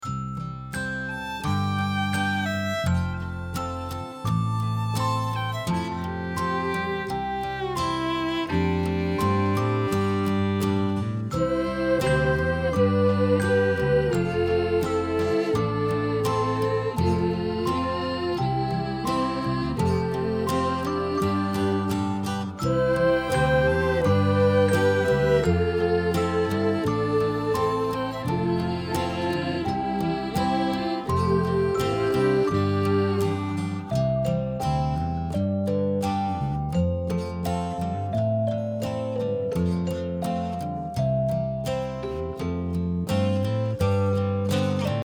Downloadable Instrumental Track